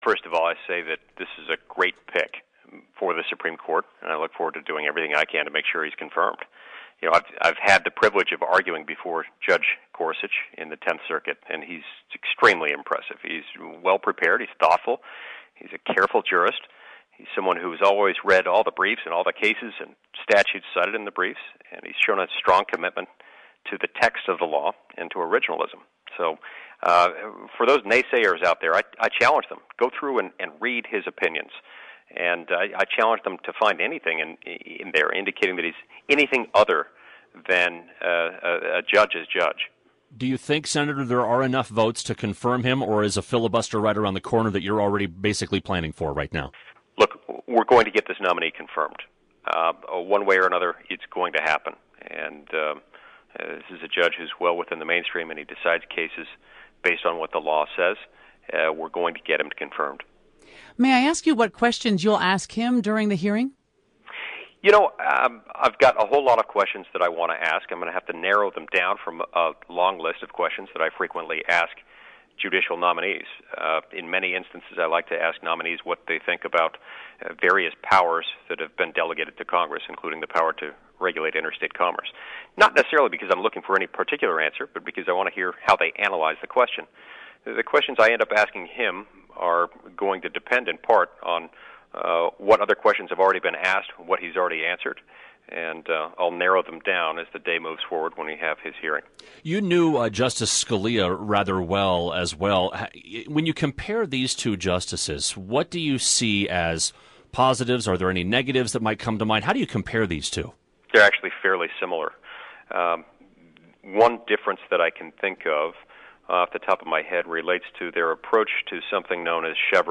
Utah Senator Mike Lee talks about Supreme Court nominee Neil Gorsuch